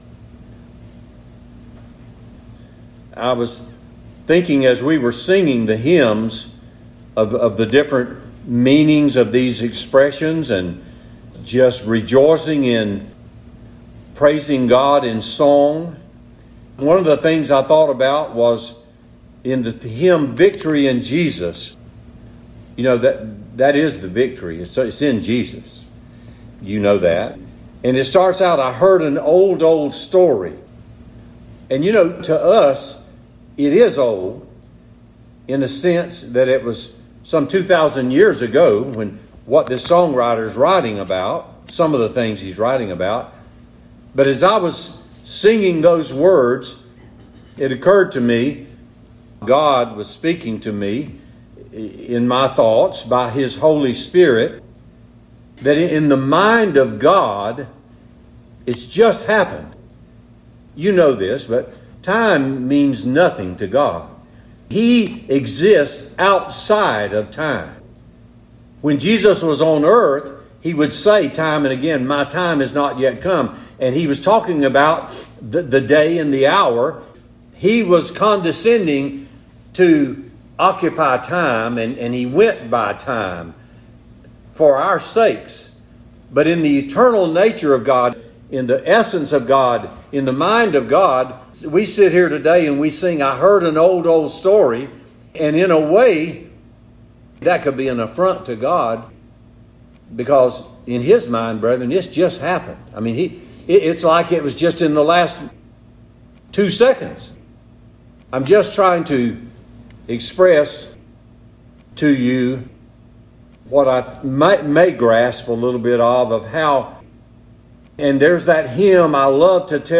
Jesus, the Good Shepherd Sep 19 In: Today's Sermon